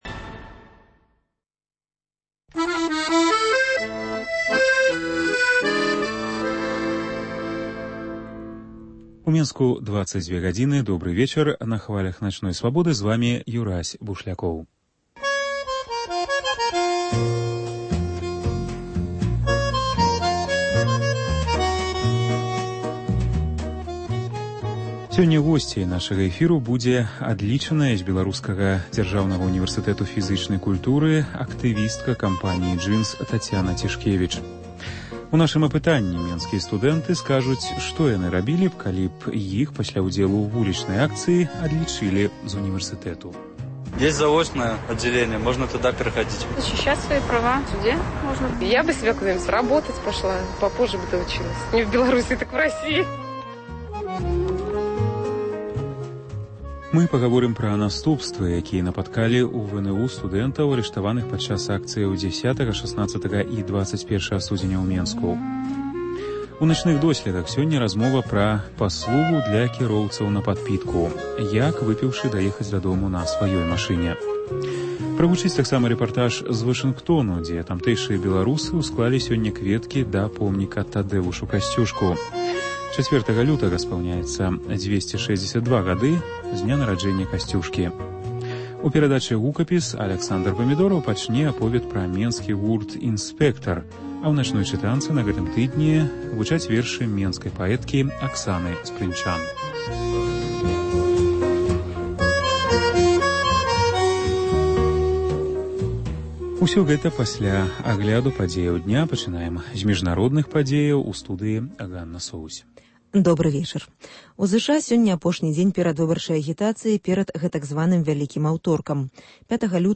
Вечаровы госьць, сацыяльныя досьледы, галасы людзей.